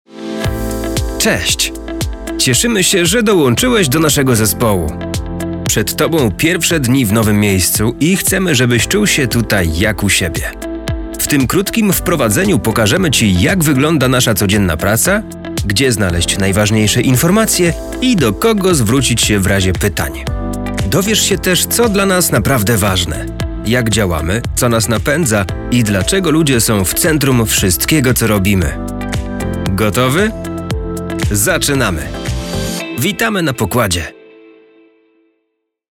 Narracje lektorskie do szkoleń, onboardingów, szkolenia, BPH, prezentacji
demo-onboarding.mp3